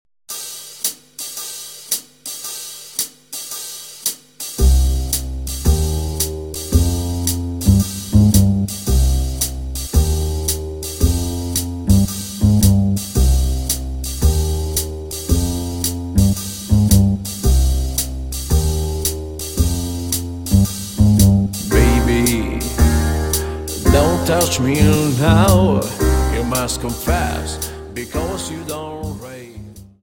Slowfox 28 Song